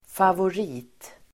Uttal: [favor'i:t]